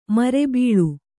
♪ mare bīḷu